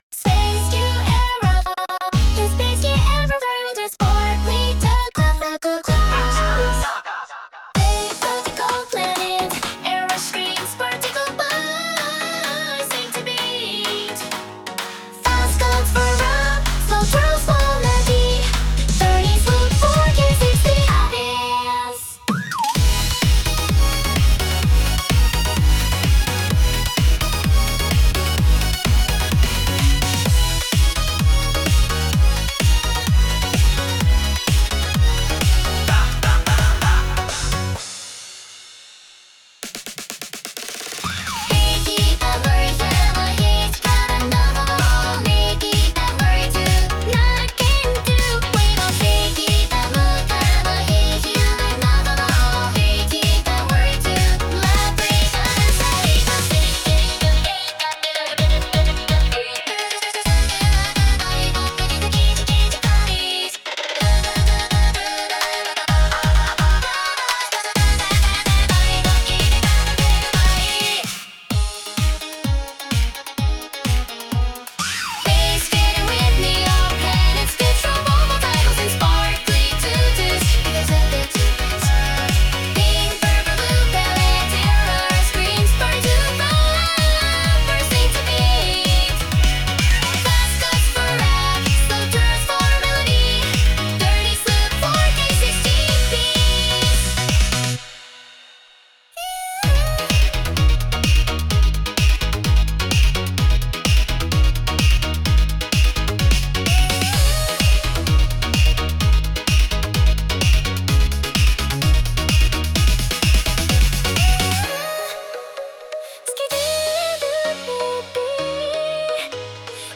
• ジャンル：エラーポップアイドル
• 声：高めでちょっとクセあり／語尾が飴っぽい